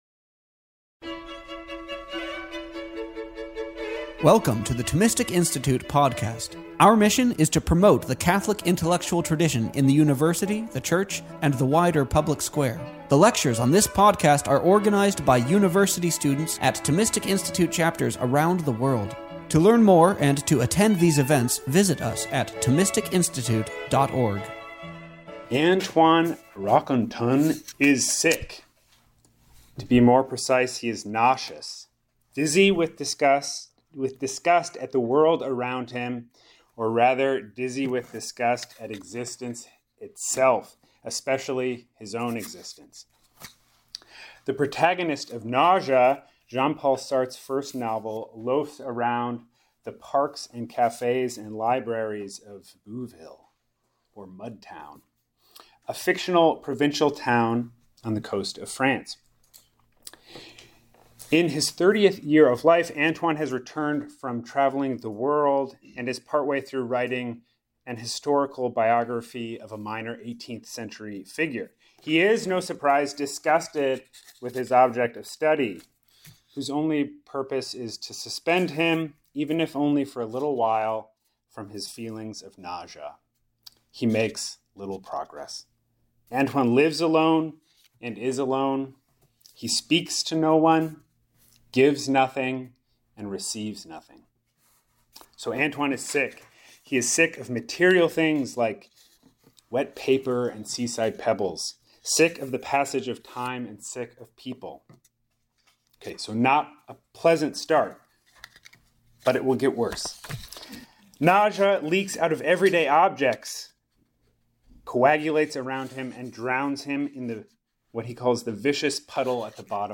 This lecture was given on April 11th, 2025, at Dominican House of Studies.